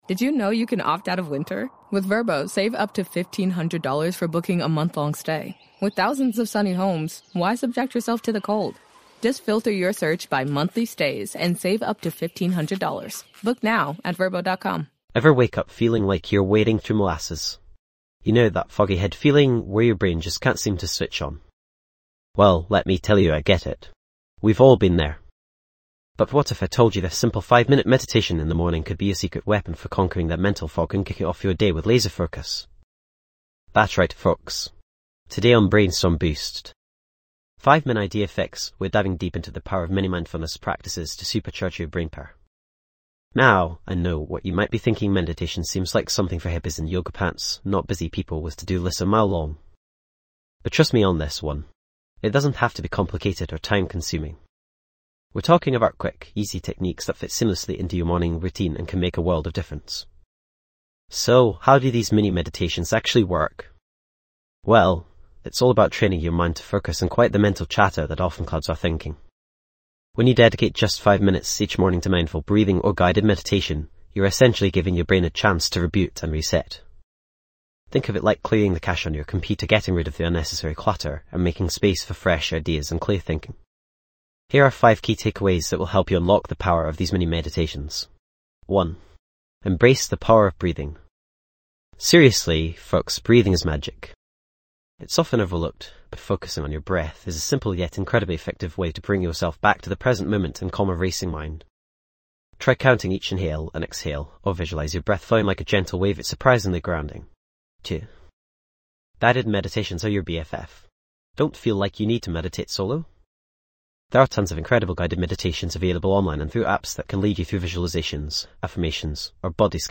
Kickstart your day with a 5-minute morning meditation designed to enhance mental clarity.
This podcast is created with the help of advanced AI to deliver thoughtful affirmations and positive messages just for you.